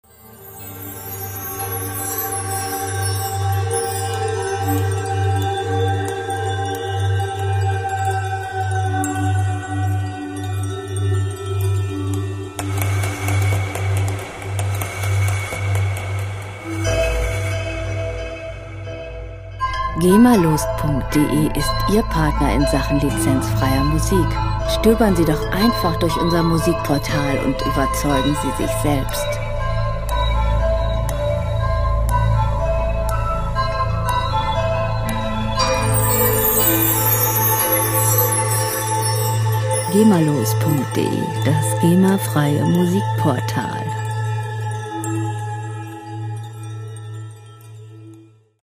Filmmusik - Landschaften
Musikstil: Ambient
Tempo: 60 bpm
Tonart: G-Moll
Charakter: geheimnisvoll, abgründig
Instrumentierung: Glockenspiel, Synthesizer